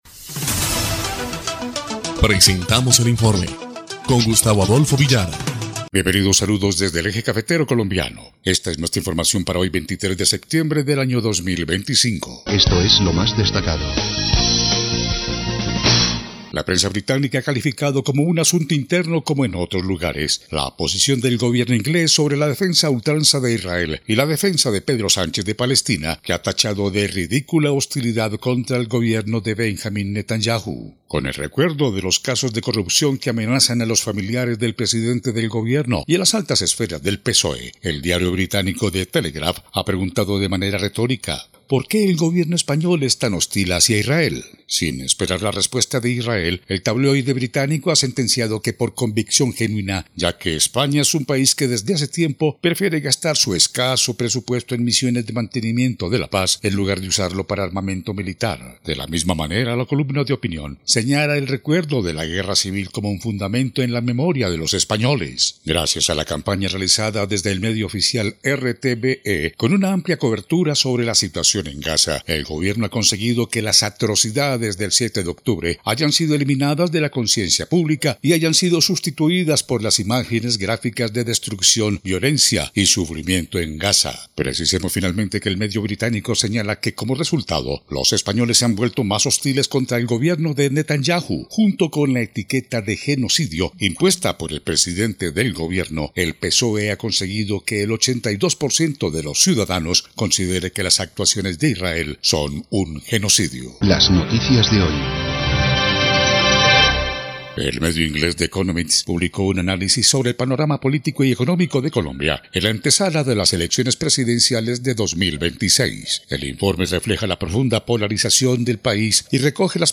EL INFORME 2° Clip de Noticias del 23 de septiembre de 2025